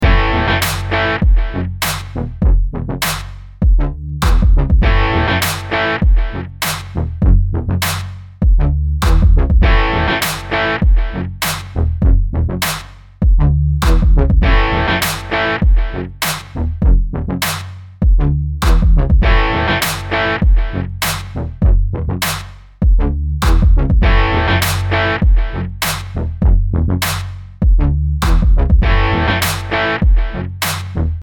black fuzz